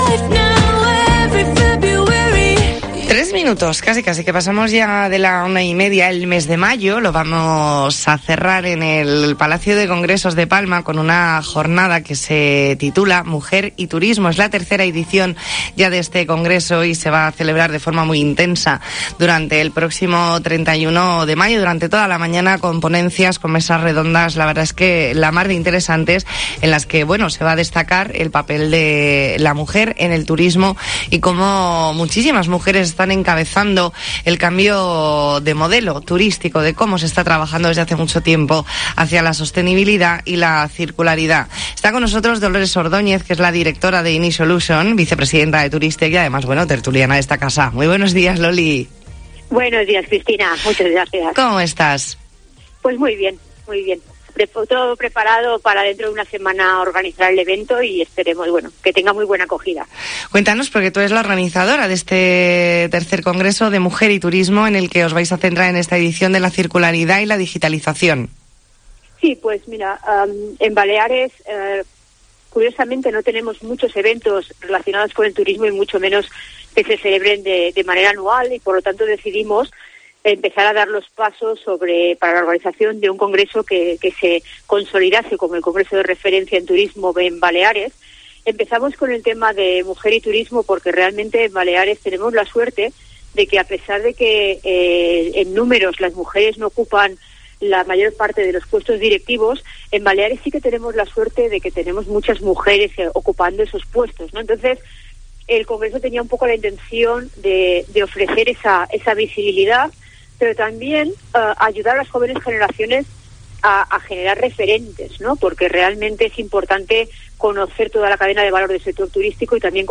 E ntrevista en La Mañana en COPE Más Mallorca, lunes 23 de mayo de 2022.